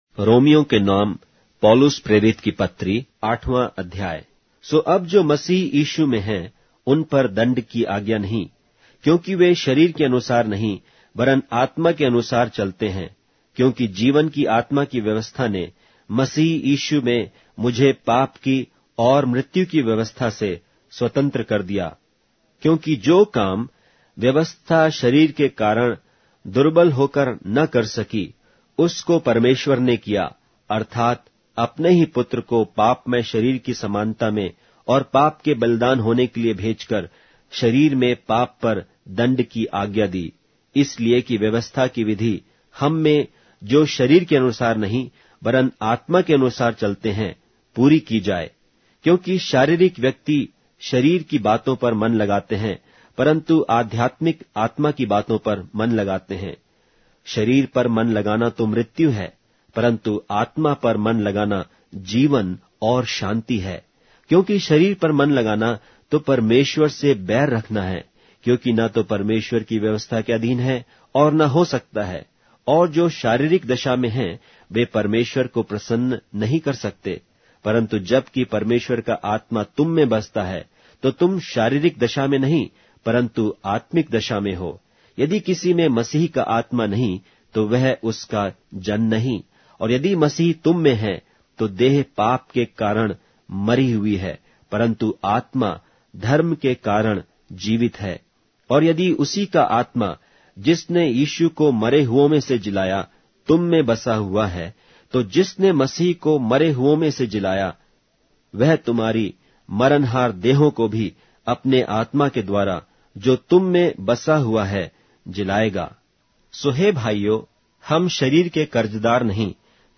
Hindi Audio Bible - Romans 11 in Ncv bible version